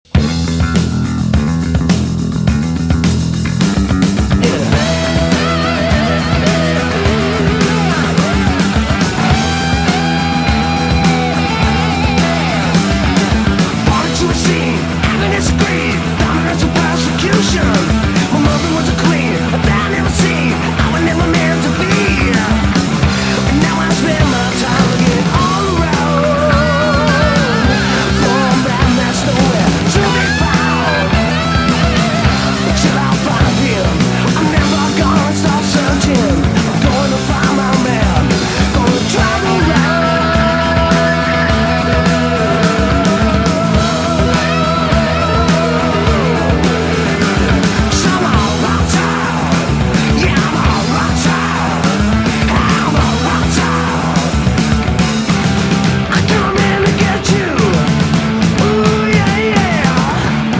Metal